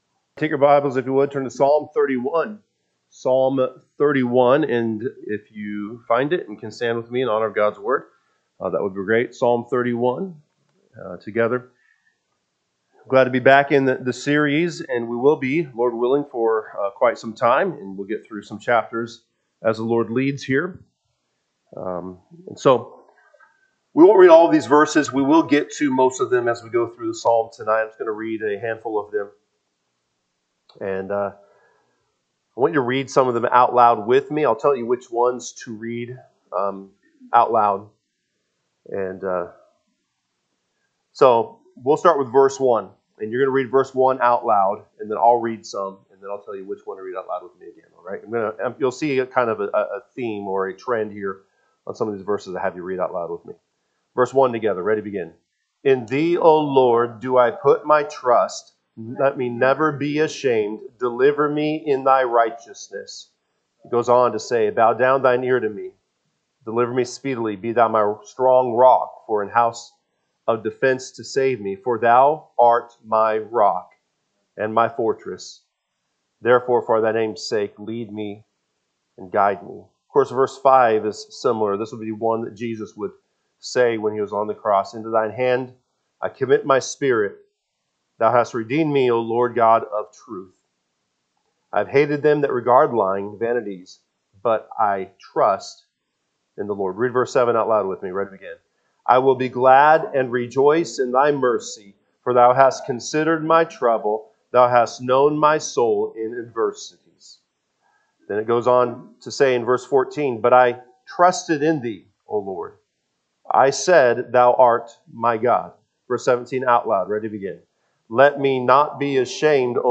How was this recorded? Wednesday Evening